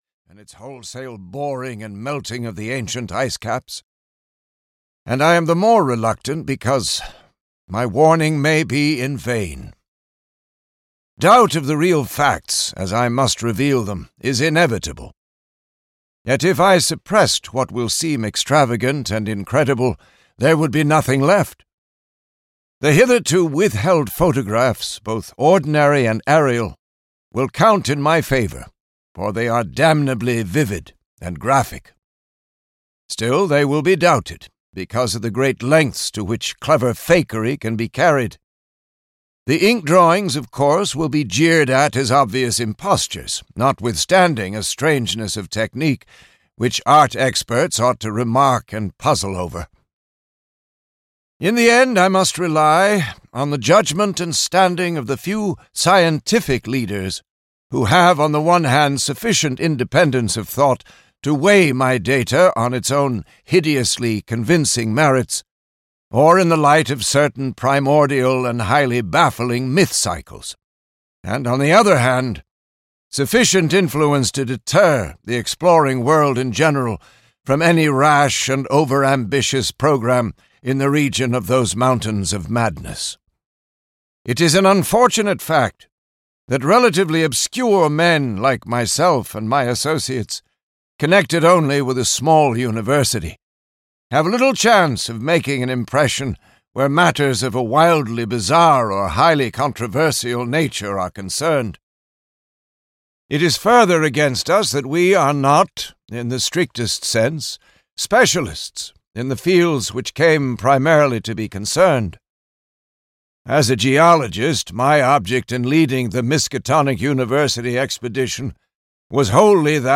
At the Mountains of Madness (EN) audiokniha
Ukázka z knihy